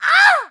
horror
Witch Hurt Grunt 2